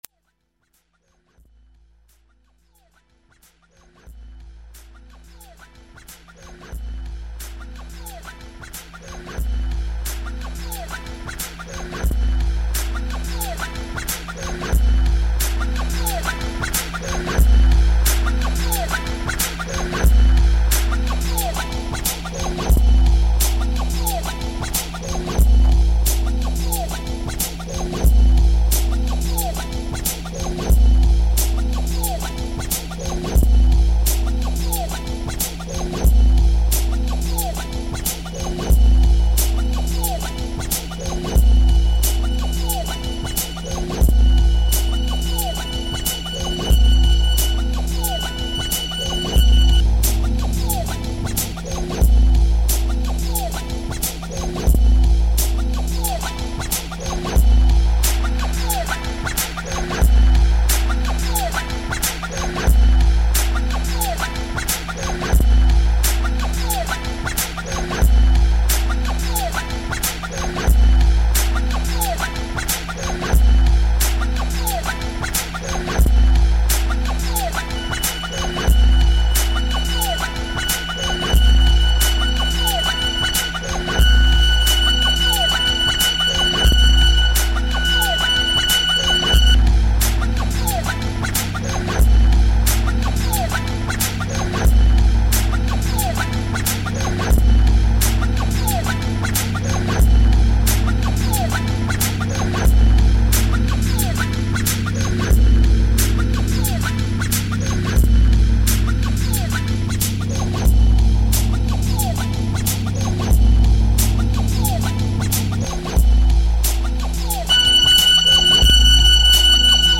File under: Minimal Electronica / Illbient